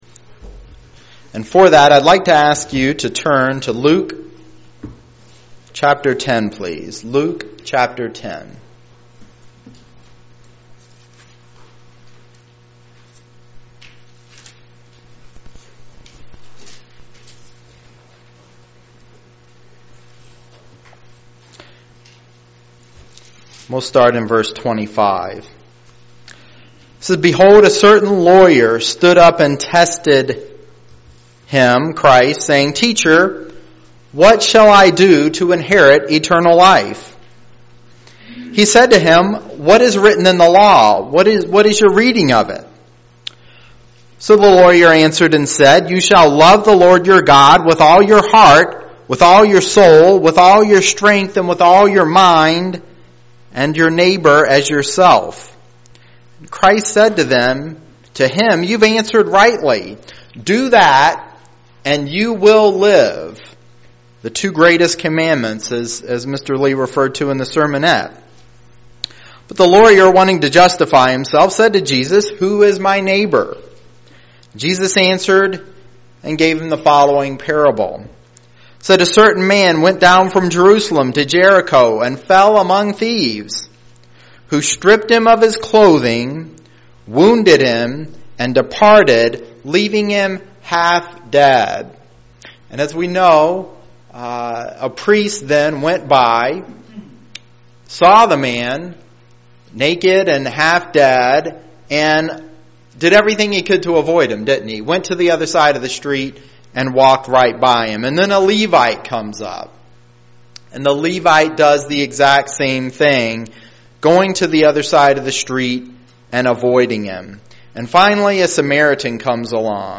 Given in Lansing, MI
UCG Sermon